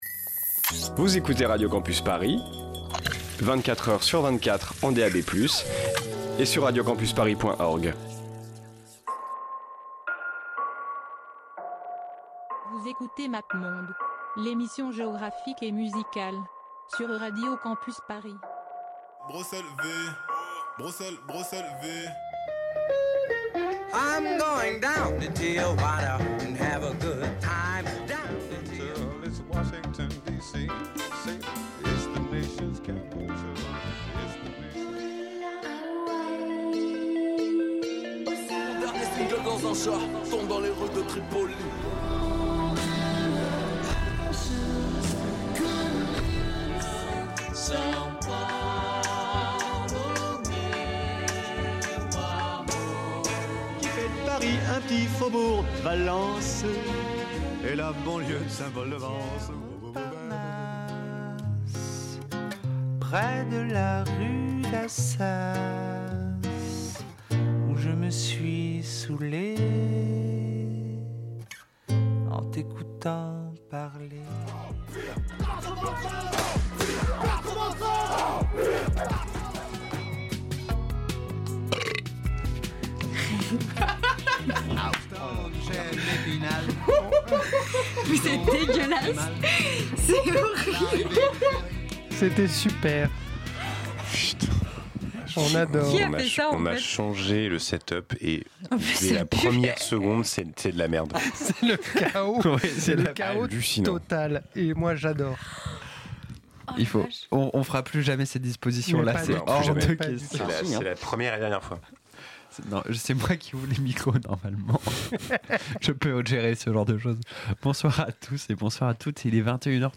La musique d'Iran
Donc après le Venezuela et le Groenland, Mappemonde s'intéresse à la musique iranienne pour la première fois depuis 2019. Au programme, de la musique téhéranaise pré-révolution, de la musique classique du Baloutchistan et des musiciens actuels issus de la diaspora iranienne.